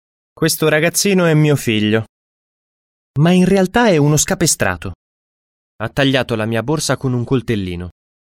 Melodic language
Italian is also widely known as a “melodic” language that sounds beautiful to the ear whether it is spoken or sung.
All of these traits makes Italian a melodic and beautiful language to the ear.